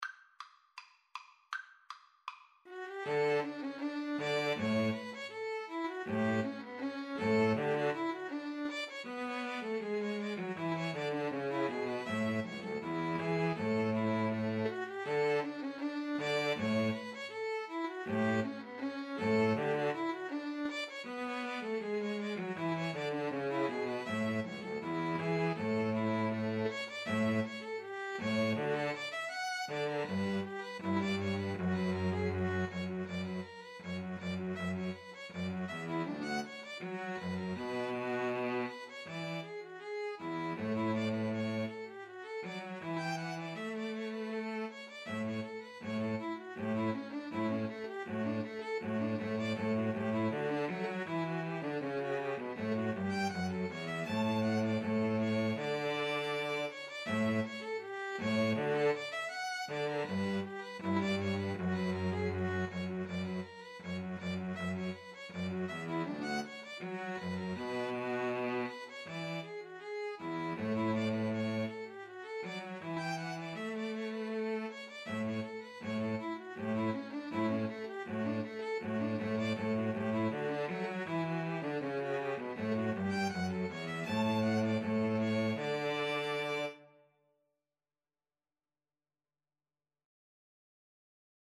D major (Sounding Pitch) (View more D major Music for String trio )
Classical (View more Classical String trio Music)